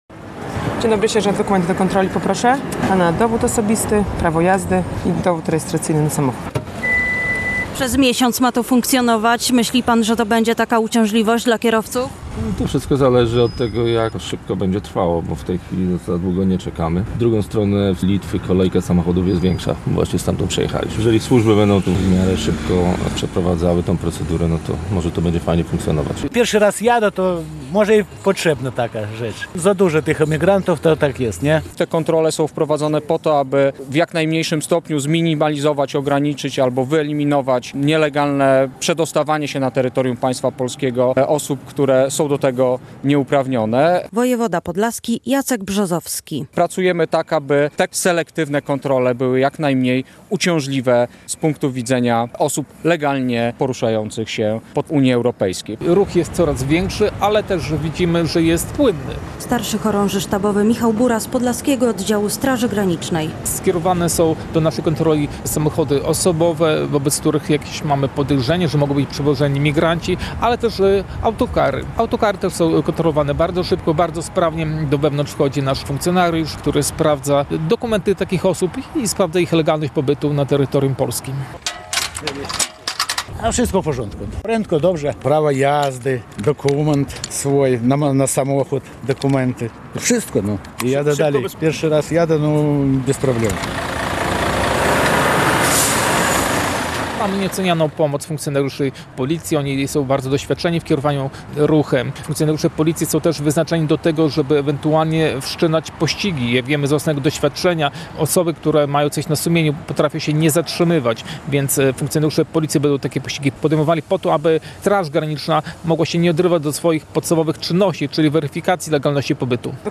Jak wyglądają kontrole na granicy polsko-litewskiej w Budzisku? - relacja